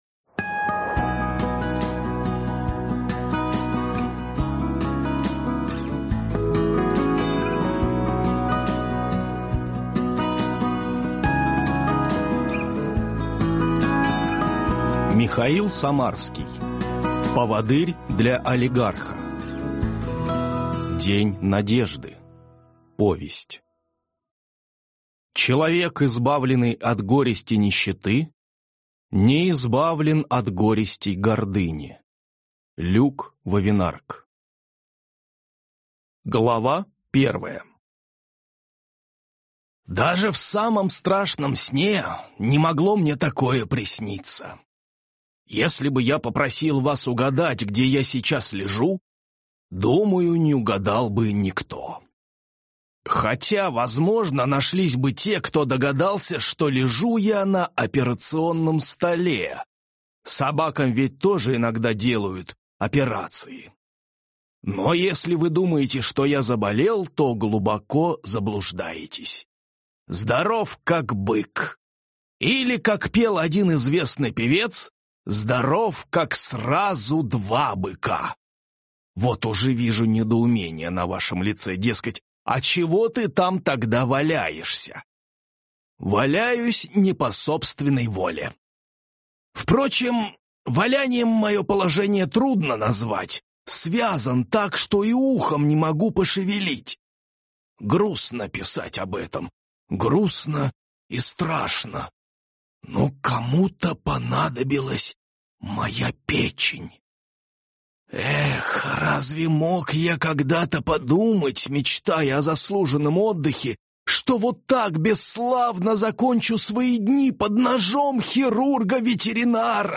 Аудиокнига Поводырь для олигарха. День надежды | Библиотека аудиокниг